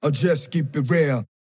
Battle Rap Vocals